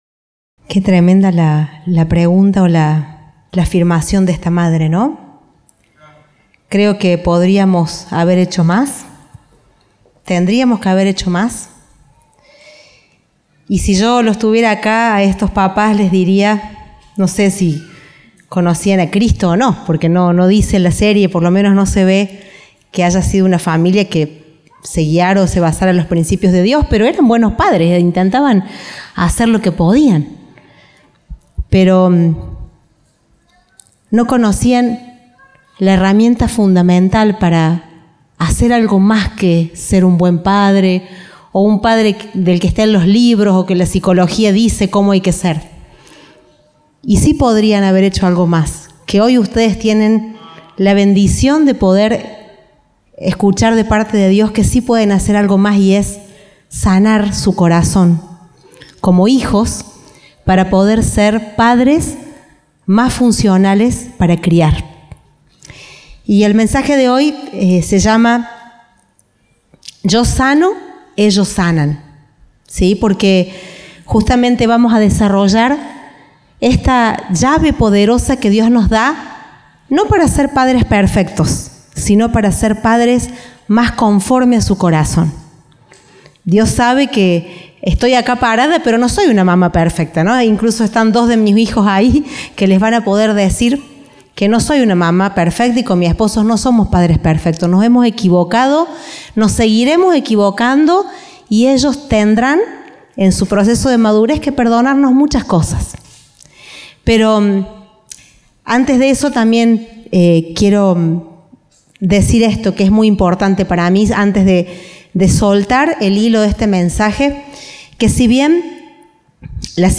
Compartimos el mensaje del Domingo 25 de Mayo de 2025.